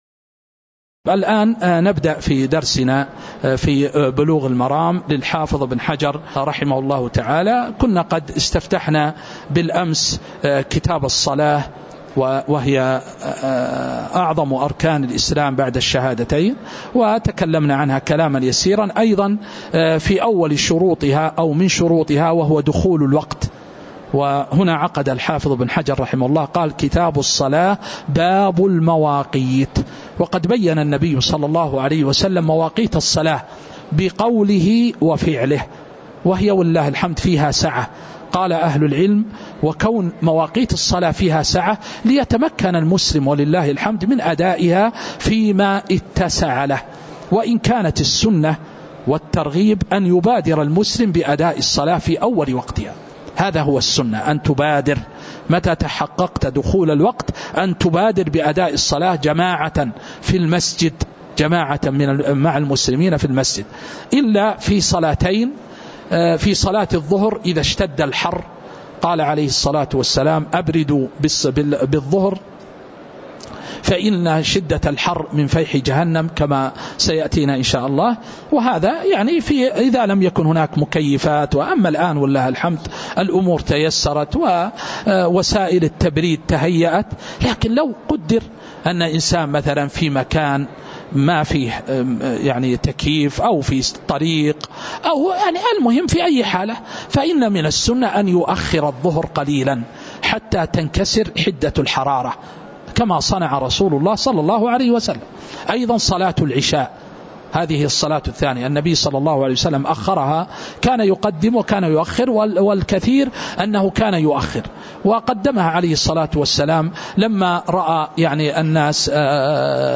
تاريخ النشر ٢٤ محرم ١٤٤٥ هـ المكان: المسجد النبوي الشيخ